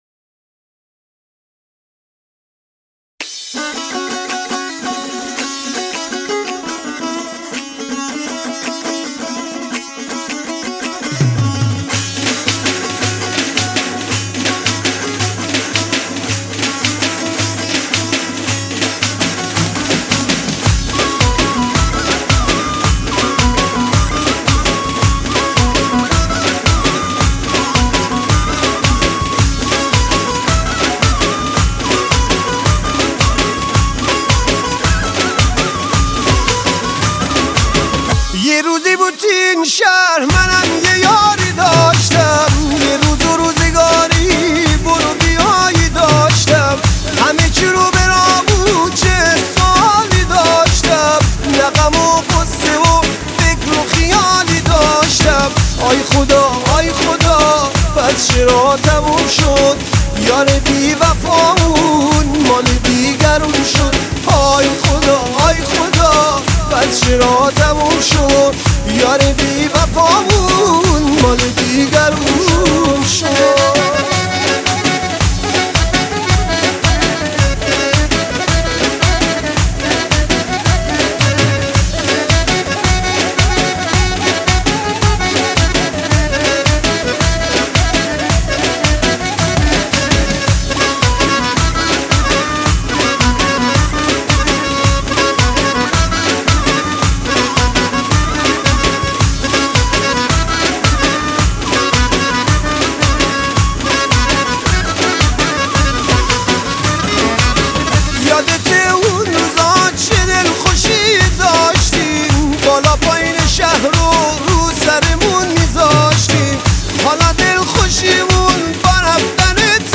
آهنگ محلی